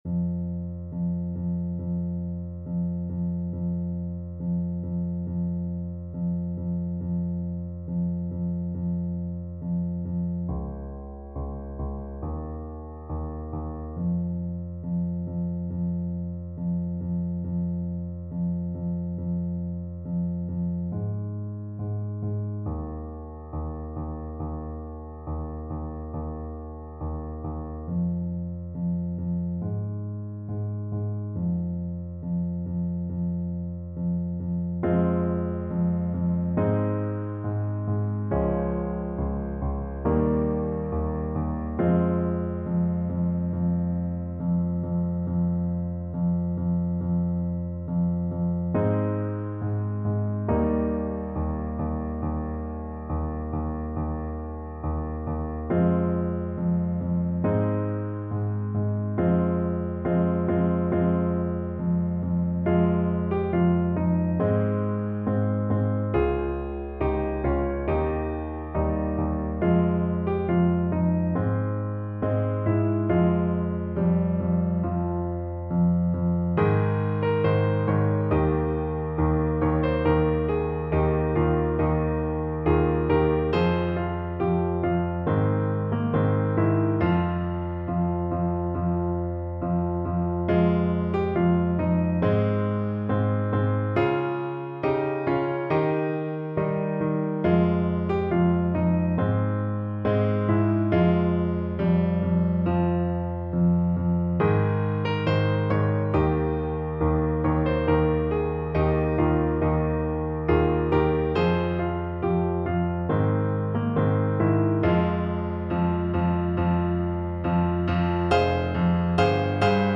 Play (or use space bar on your keyboard) Pause Music Playalong - Piano Accompaniment Playalong Band Accompaniment not yet available transpose reset tempo print settings full screen
Alto Saxophone
F minor (Sounding Pitch) D minor (Alto Saxophone in Eb) (View more F minor Music for Saxophone )
Moderato =c.100
Classical (View more Classical Saxophone Music)